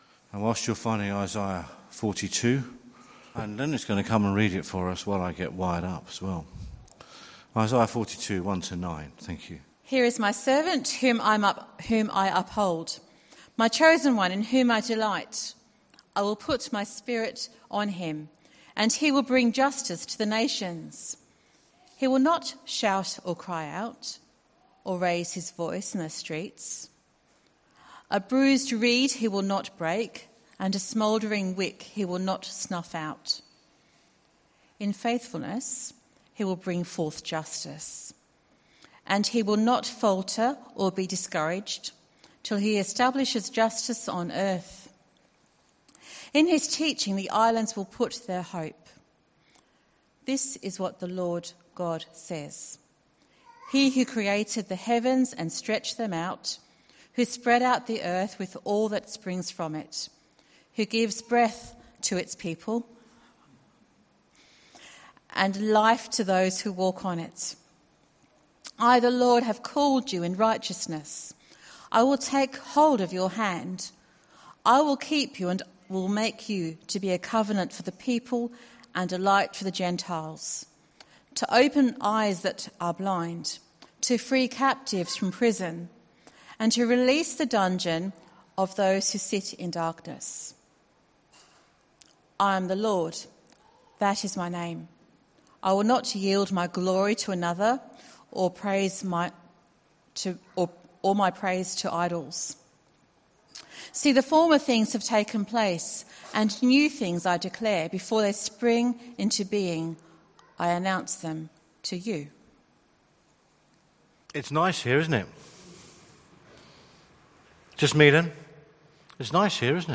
Bible reading and sermon from the 11:00AM meeting on 27th September at Newcastle Worship & Community Centre.